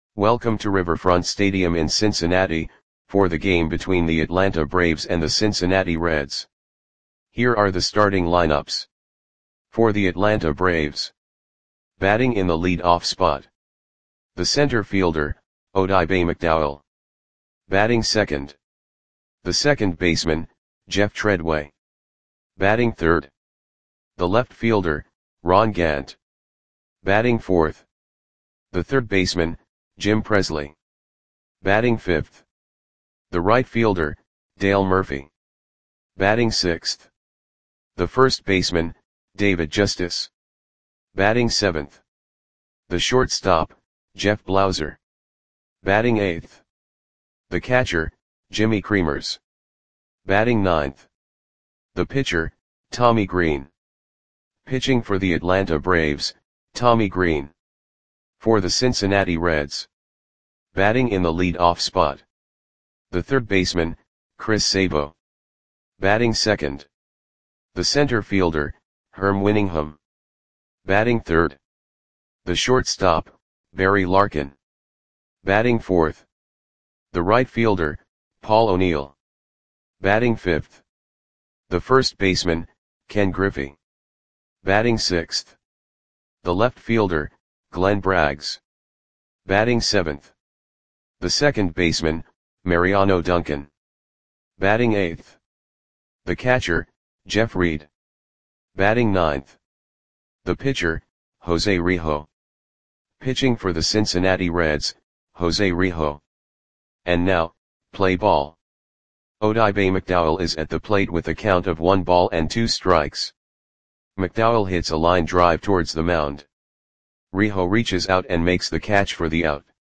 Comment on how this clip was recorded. Lineups for the Cincinnati Reds versus Atlanta Braves baseball game on June 12, 1990 at Riverfront Stadium (Cincinnati, OH).